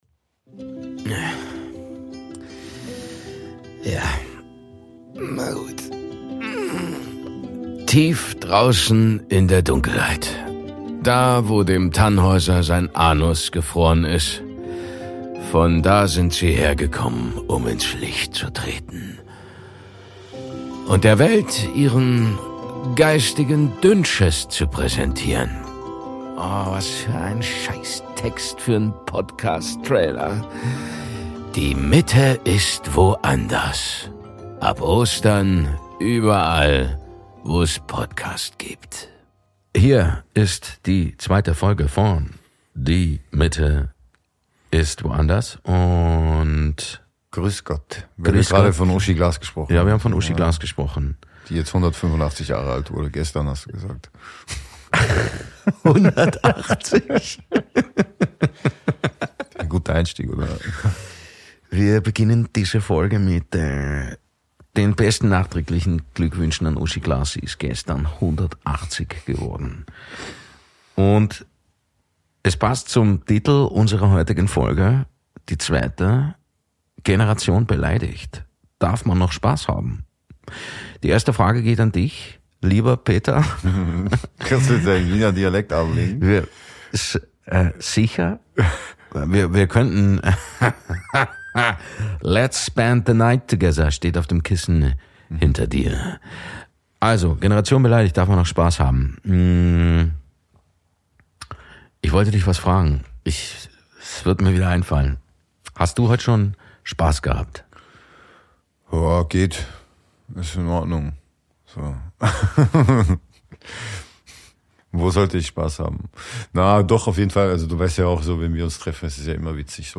und Rapper und Musiker Chakuza.